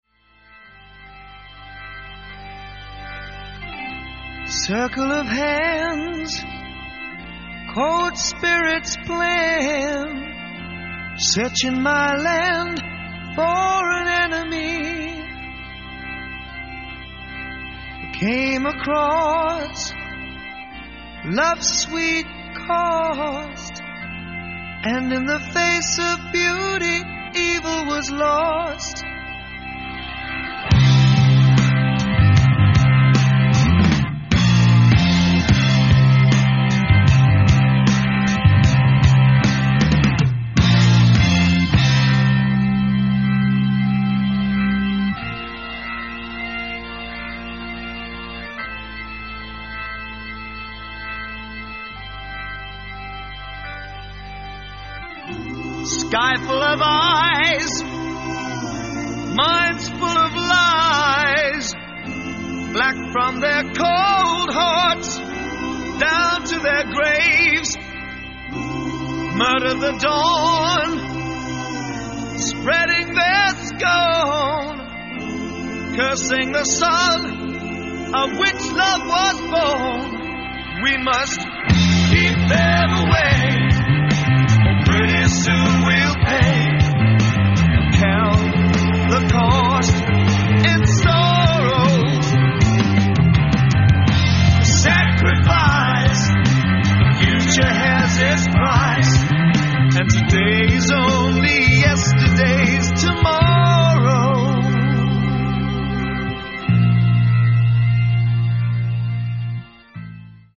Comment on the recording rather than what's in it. Lansdowne Studios, London